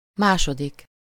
Ääntäminen
Ääntäminen Tuntematon aksentti: IPA: /ˈanˌdra/ Haettu sana löytyi näillä lähdekielillä: ruotsi Käännös Ääninäyte 1. második Andra on sanan annan taipunut muoto.